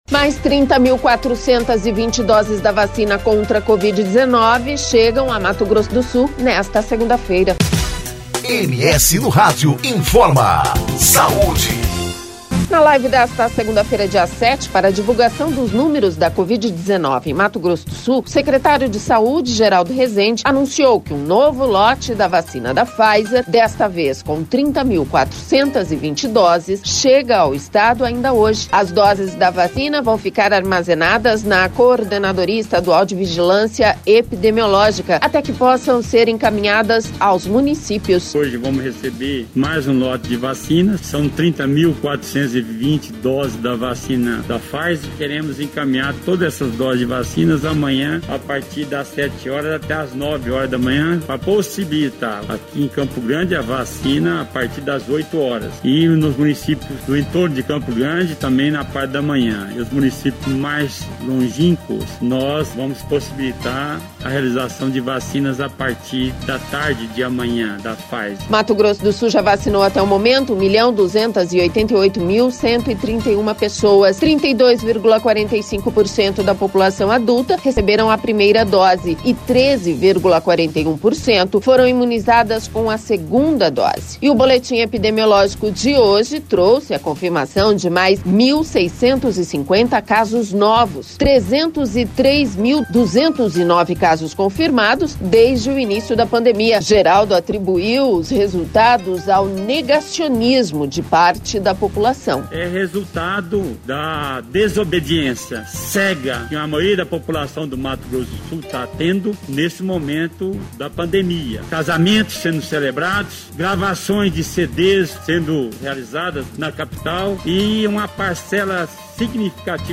Na live desta segunda-feira, dia 07, para divulgação dos números da Covid-19 em Mato Grosso do Sul, o secretário de saúde, Geraldo Resende anunciou que um novo lote da vacina da Pfizer, desta vez com 30.420 doses chega ao Estado, ainda hoje.